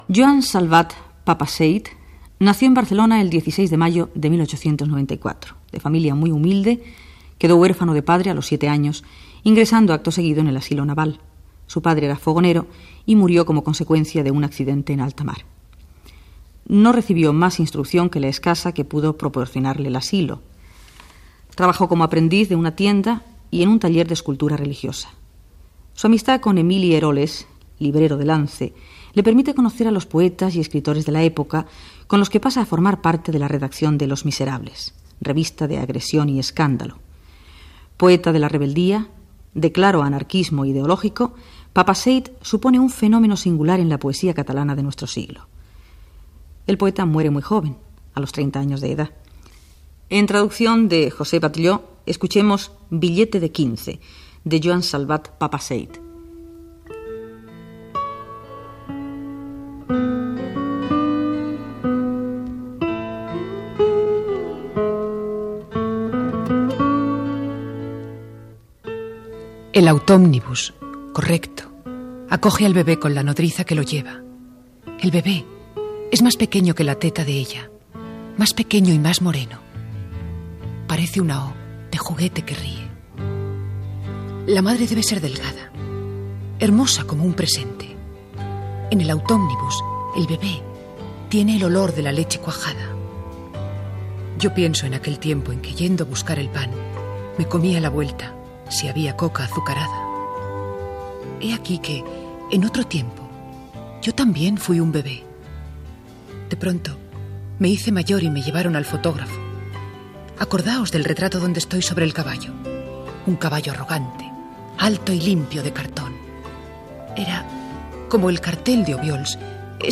Perfil biogràfic del poeta Joan Salvat Papasseit i recitat en castellà del poema seu "Bitllet de quinze"